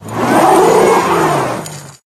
wheel_accelerate_01.ogg